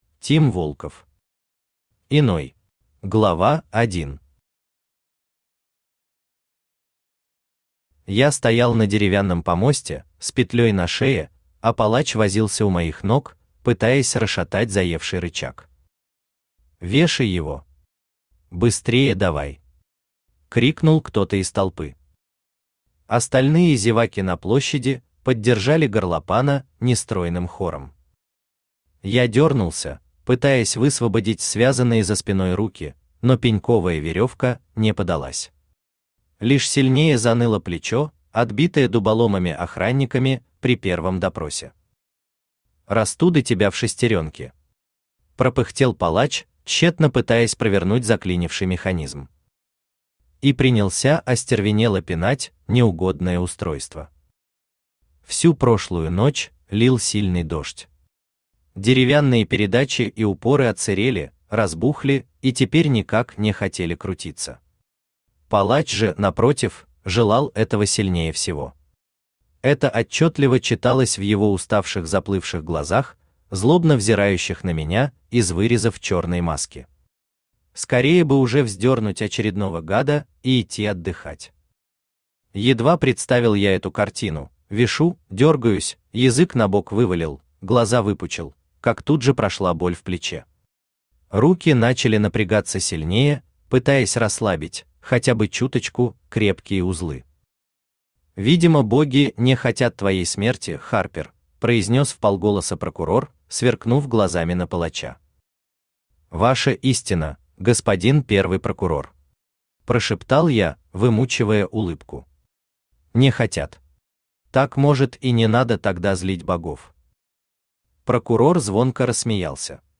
Аудиокнига Иной | Библиотека аудиокниг
Aудиокнига Иной Автор Тим Волков Читает аудиокнигу Авточтец ЛитРес.